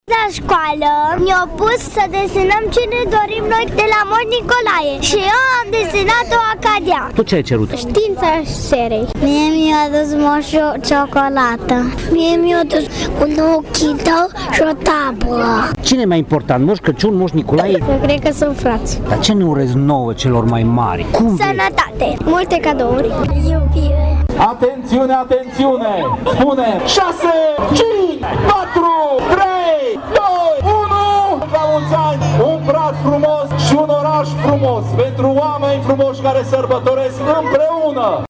Joi seara, pentru al zecelea an, de Sfântul Nicolae, mii de târgumureșeni au înconjurat bradul amplasat în locul Ceasului Floral din centrul urbei.
Sute de copii au așteptat, în pofida frigului pătrunzător, inaugurarea iluminatul public și au numărat împreună cu primarul Dorin Florea până zecile de mii de beculețe au fost aprinse: